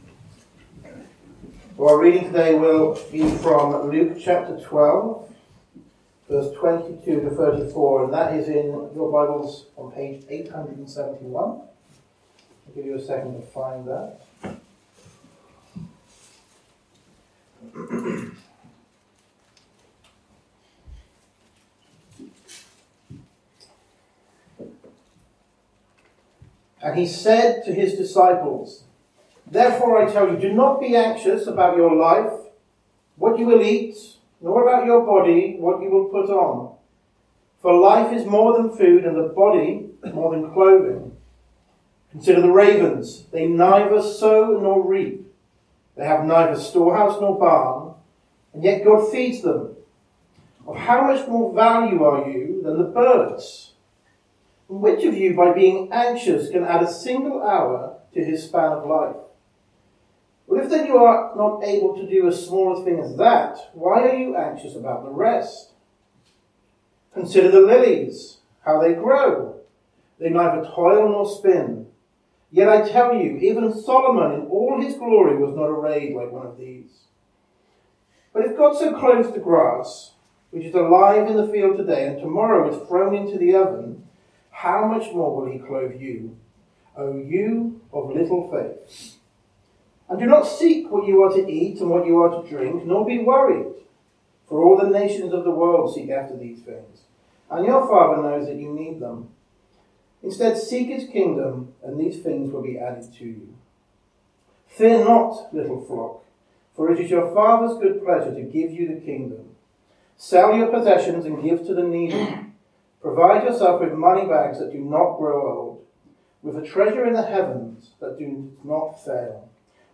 A link to the video recording of the 6:00pm service and an audio recording of the sermon.